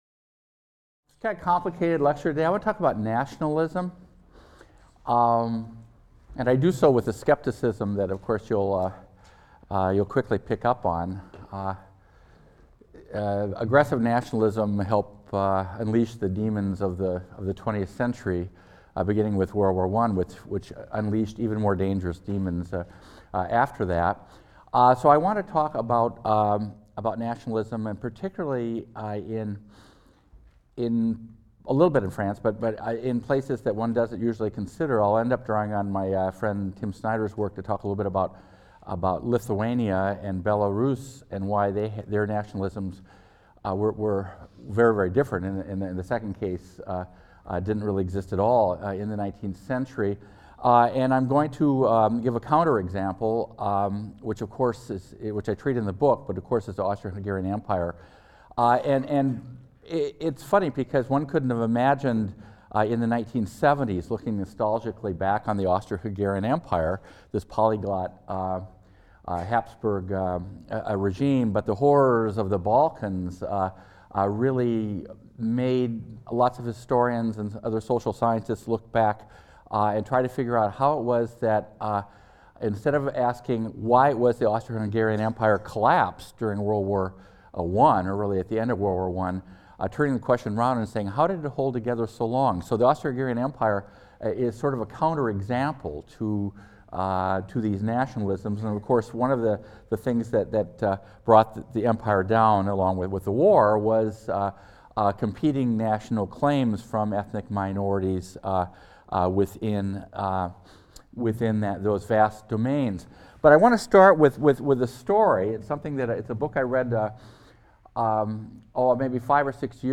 HIST 202 - Lecture 13 - Nationalism | Open Yale Courses